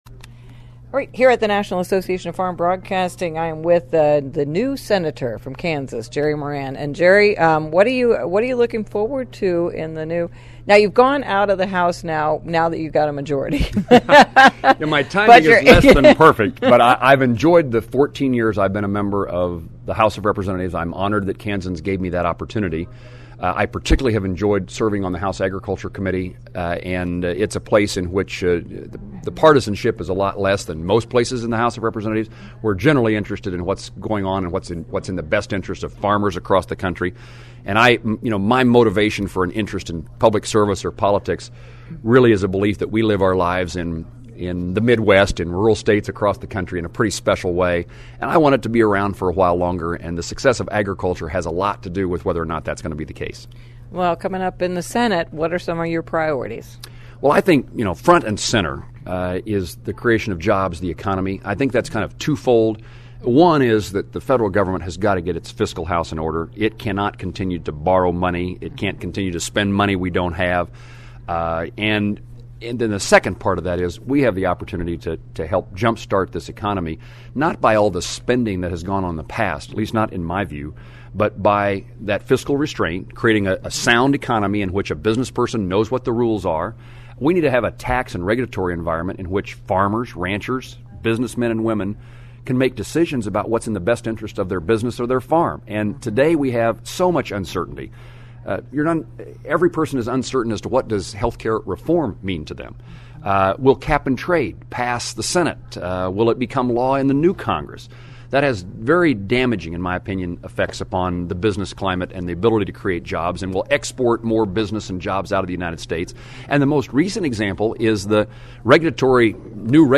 Current Rep. Jerry Moran (R-KS), who is now Senator-Elect for that state, stopped by the NAFB meeting on his way out to the nation’s capitol on Friday.
I had the opportunity to interview Jerry about his priorities in the new congress and his opinion on important issues like biofuels and GIPSA. Listen to my interview with Jerry Moran here: